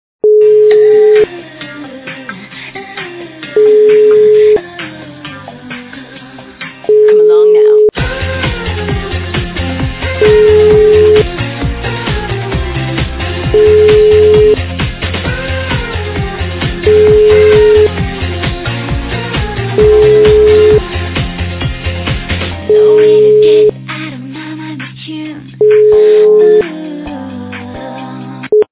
- западная эстрада
При заказе вы получаете реалтон без искажений.